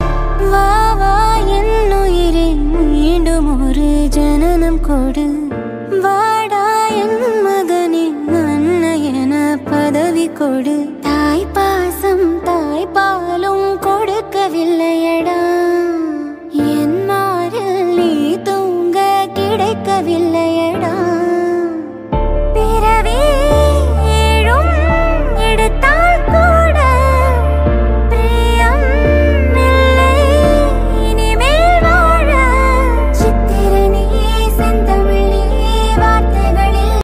Best Ringtones, Tamil Ringtones